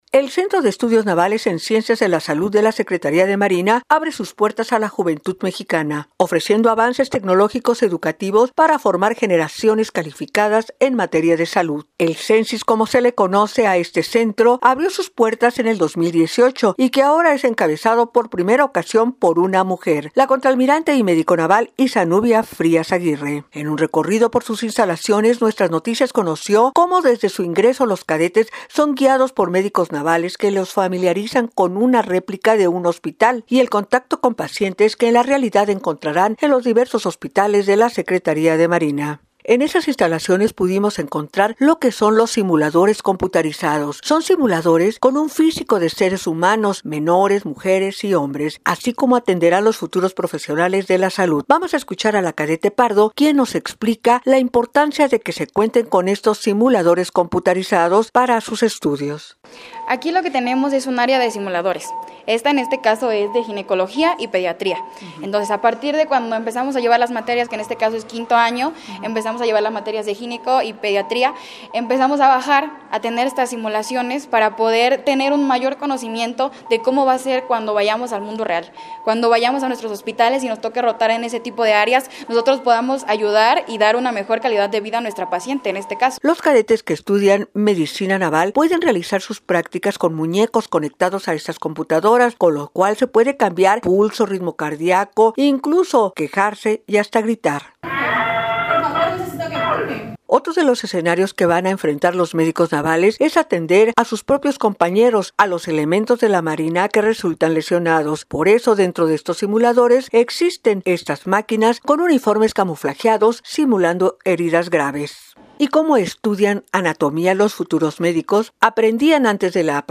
NOTA-CONVOCATORIA-ESTUDIAR-MARINA-RADIO.mp3